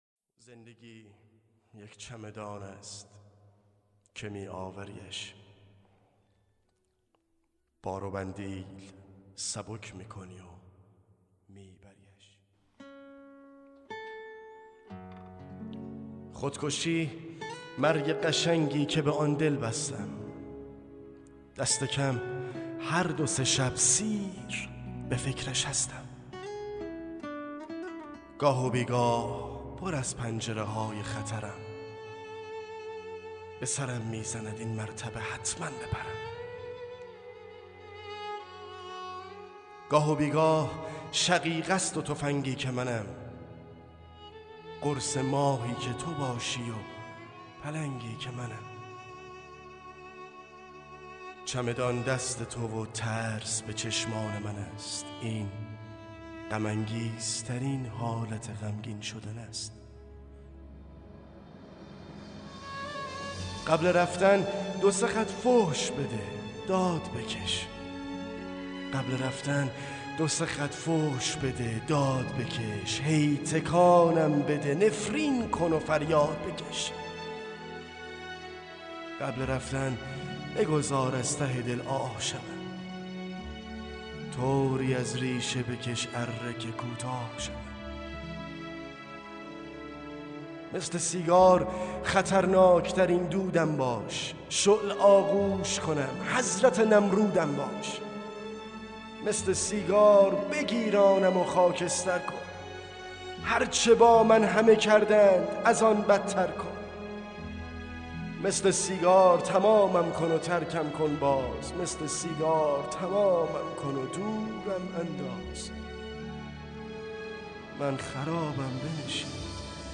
دانلود دکلمه تومور دو با صدای علیرضا آذر
گوینده :   [علیرضا آذر]